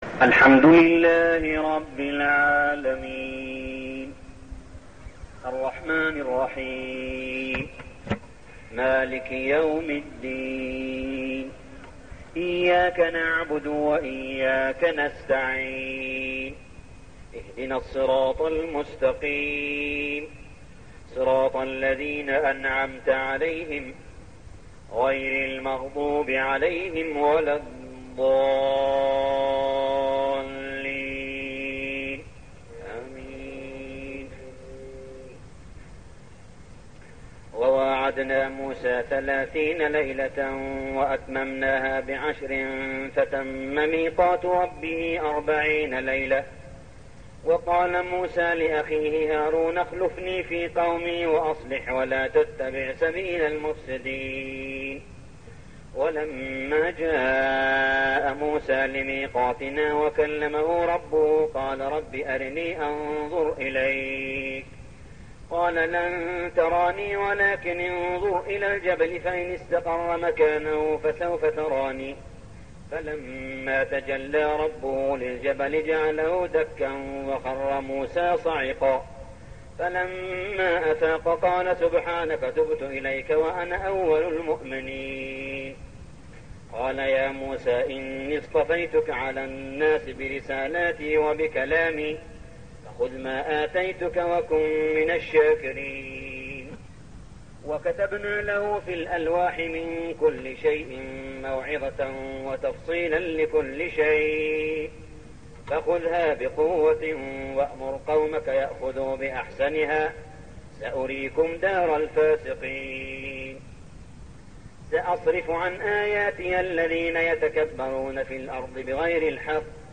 صلاة التراويح ليلة 9-9-1413هـ | سورتي الأعراف 142-206 و الأنفال 1-23 > تراويح الحرم المكي عام 1413 🕋 > التراويح - تلاوات الحرمين